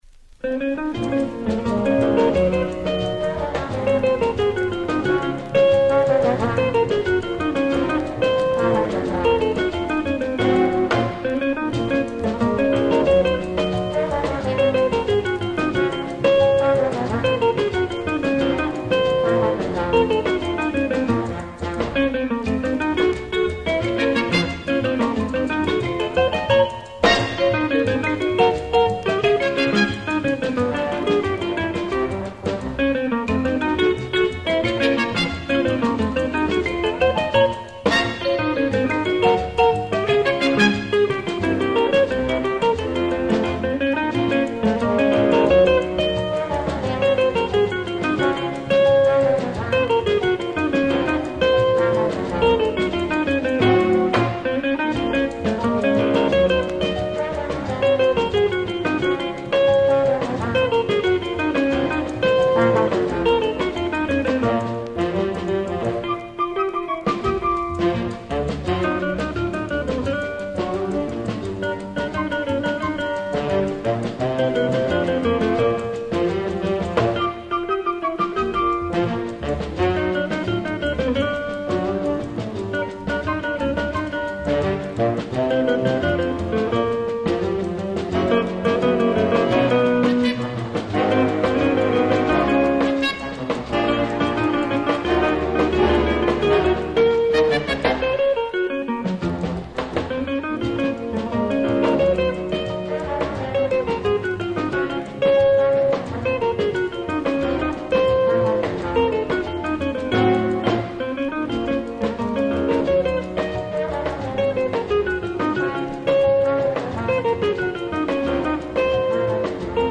Chitarra solista